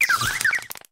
tadbulb_ambient.ogg